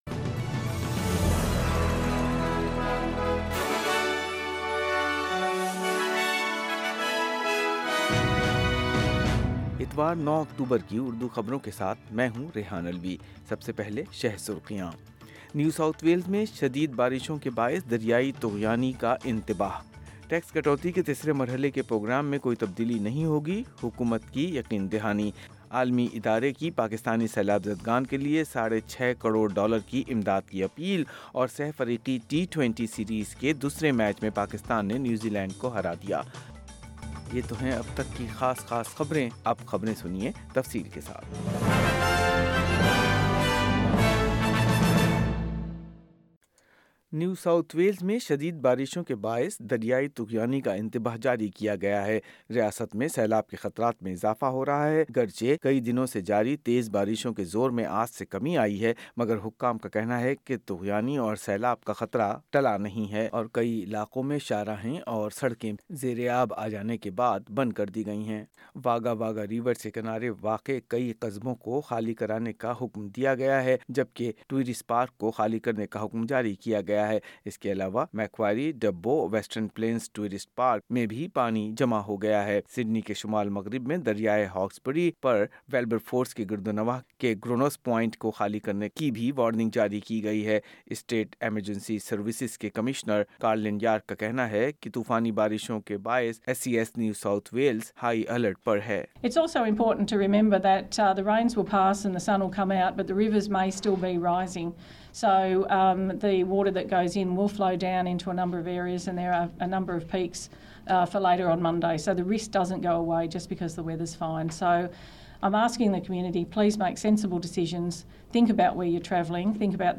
Full news bulletin in Urdu - Sunday 9 October 2022